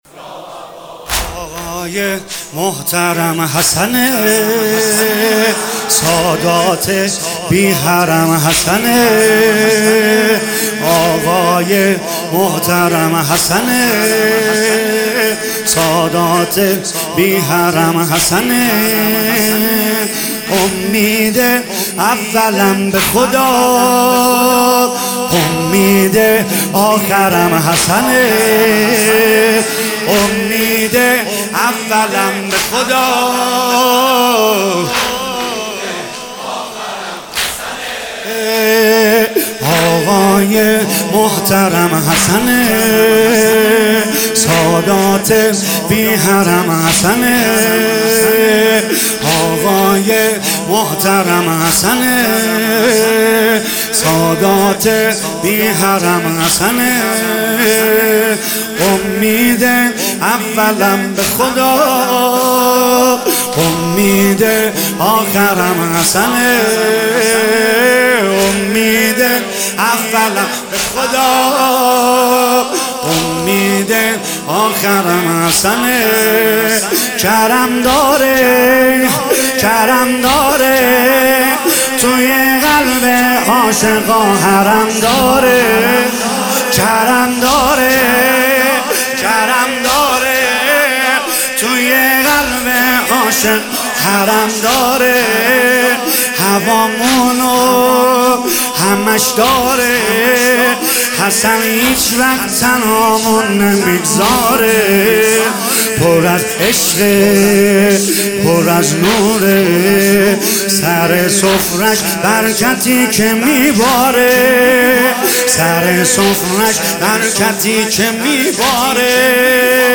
تولید شده: هیئت فدائیان حسین (ع) اصفهان سیدرضا نریمانی